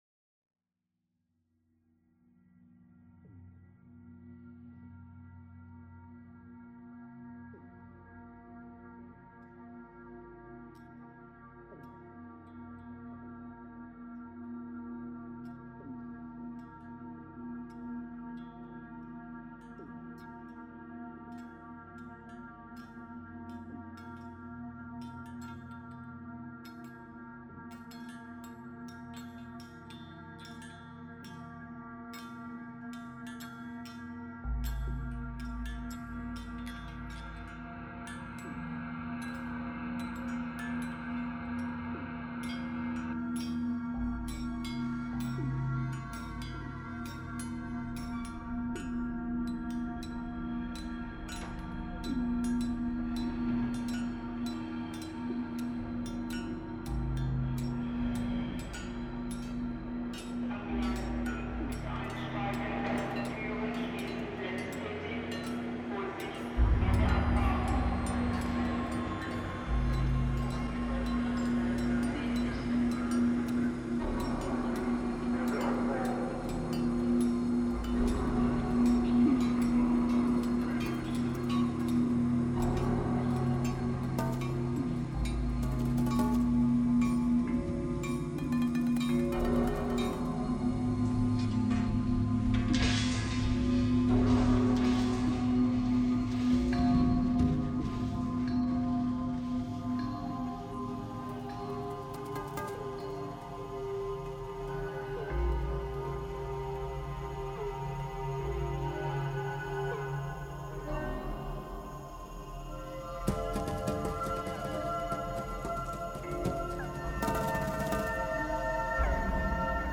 L'extatique inconfort de la désobéissance - Décomposition musicale libre